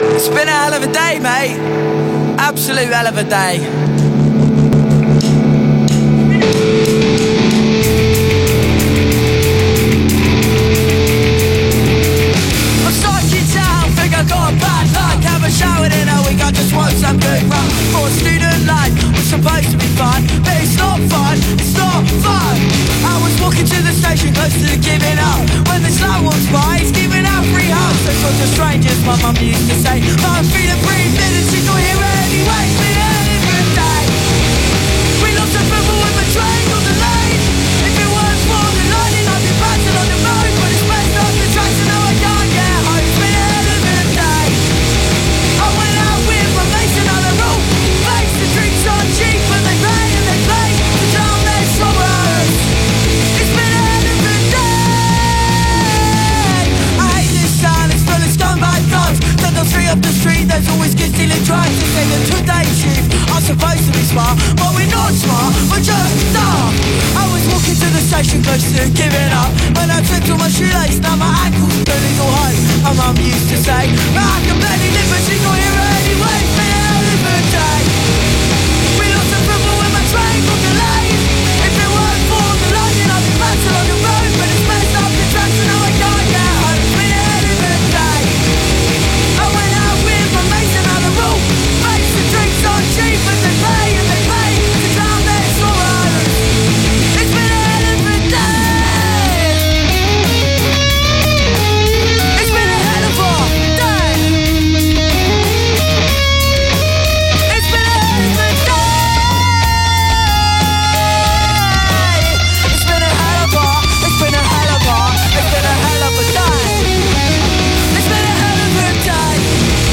Punk teens
on vocals and guitar
on bass
on drums